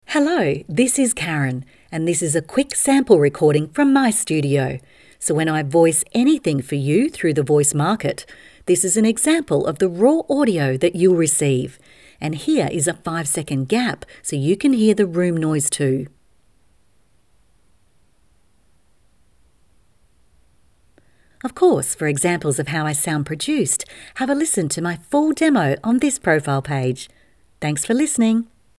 • Studio Sound Check